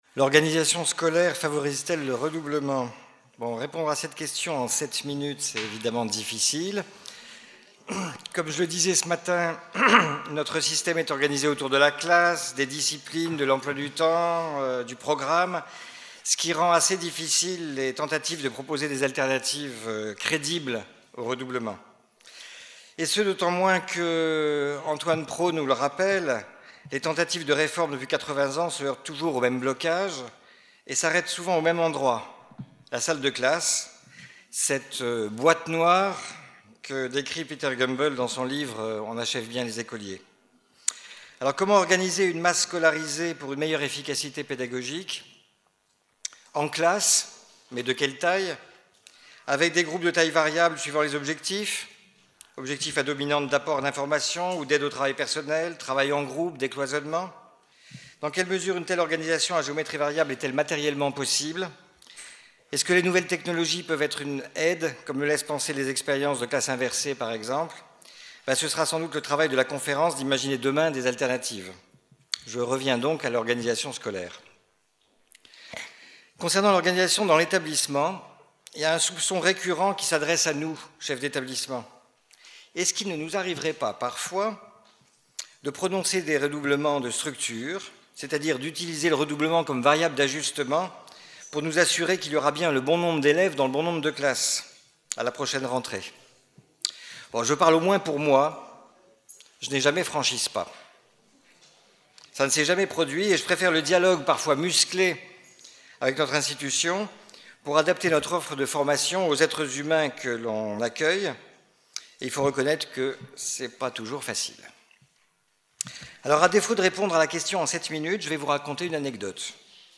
Témoignage d’un praticien : L’organisation scolaire favorise-t-elle le redoublement ?